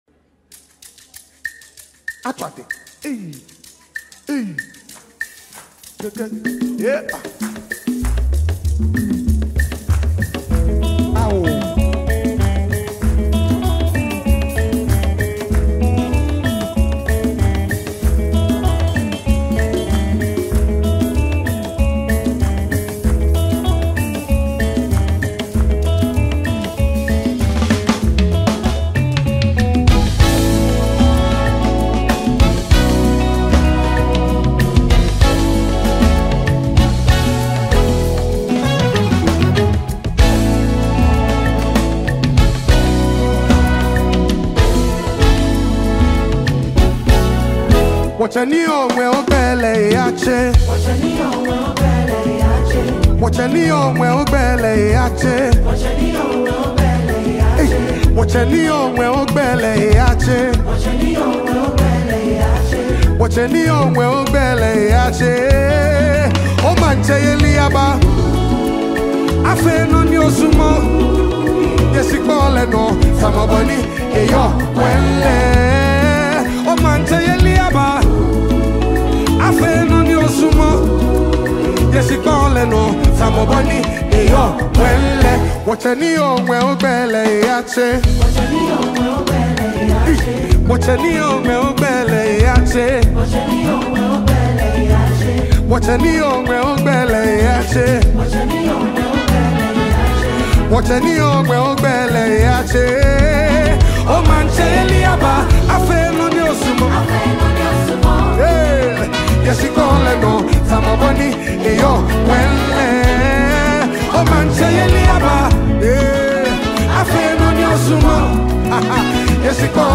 Gospel Music
worship song
Built on deep worship and soulful melodies
creates a calm yet spiritually uplifting atmosphere
passionate and sincere vocals